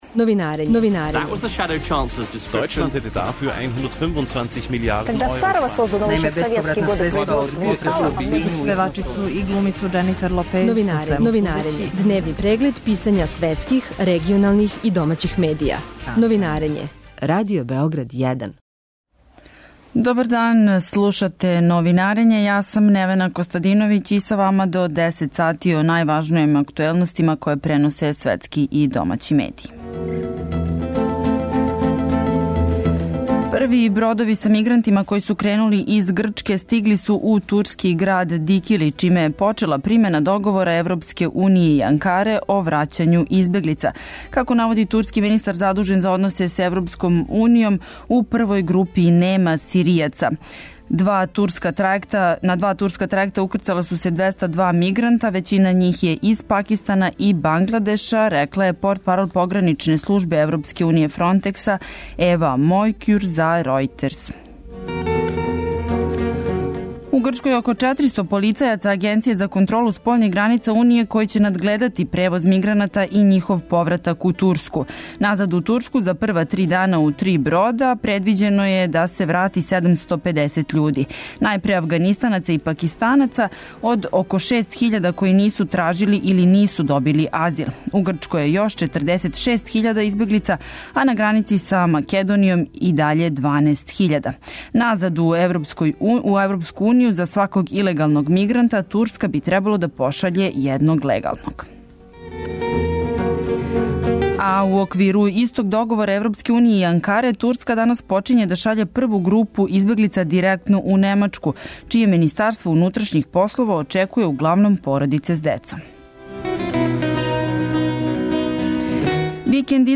Гост Новинарења је бивши амбасадор Србије у Белорусији Срећко Ђукић.